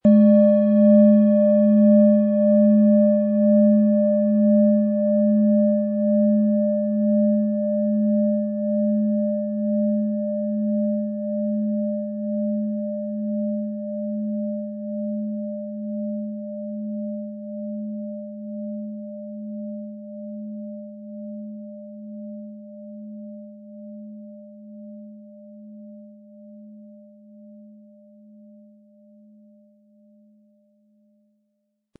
Planetenschale® Öffnet für Träume & Besser einschlafen mit Neptun, Ø 16,8 cm, 700-800 Gramm inkl. Klöppel
Planetenton 1
Wie klingt diese tibetische Klangschale mit dem Planetenton Neptun?
SchalenformBihar
MaterialBronze